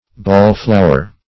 Search Result for " ball-flower" : The Collaborative International Dictionary of English v.0.48: Ball-flower \Ball"-flow`er\, n. (Arch.)